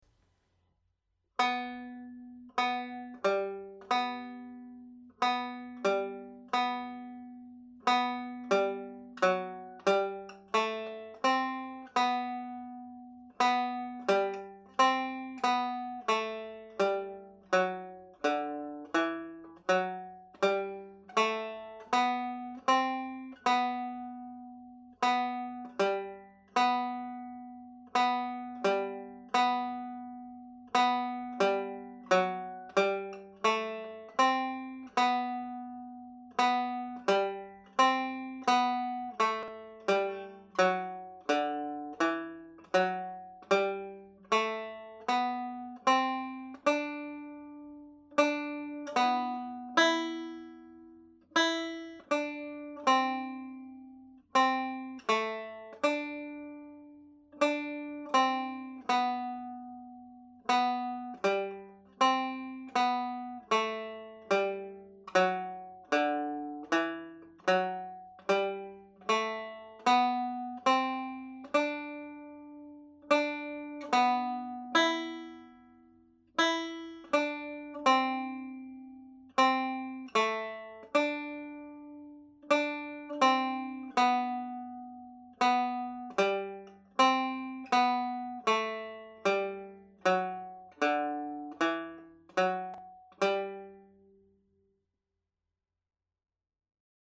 • banjo scale
The Fairy Dance reel  played slowly